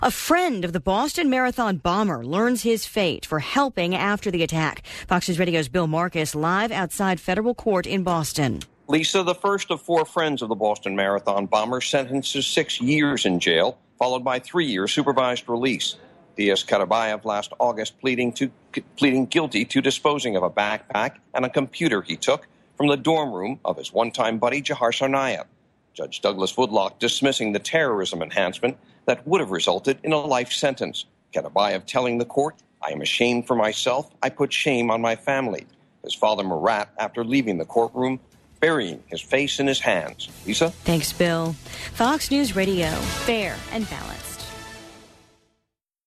(BOSTON) JUNE 2 – 12 NOON LIVE –